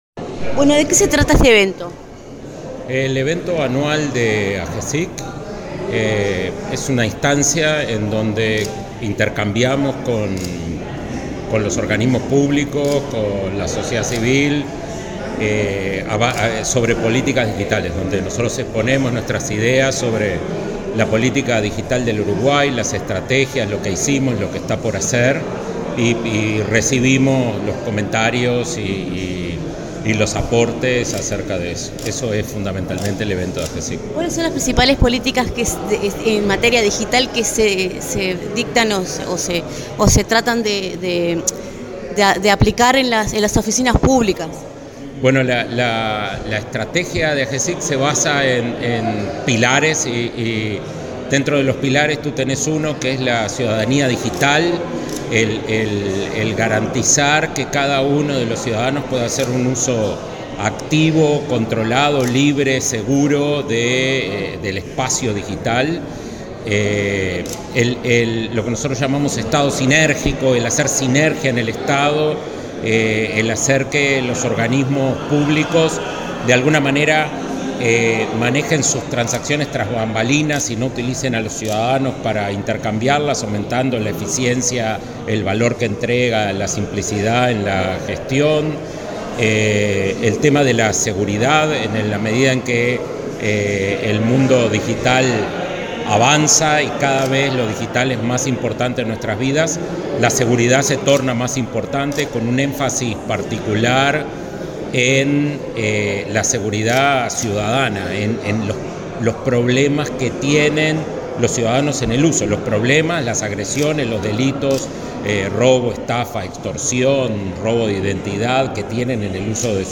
Declaraciones del director de Agesic, Daniel Mordecki
Declaraciones del director de Agesic, Daniel Mordecki 26/11/2025 Compartir Facebook X Copiar enlace WhatsApp LinkedIn En el marco del evento anual de Agencia de Gobierno Electrónico y Sociedad de la Información y del Conocimiento (Agesic), denominado “El mundo es digital”, el director del organismo, Daniel Moredecki, realizó declaraciones.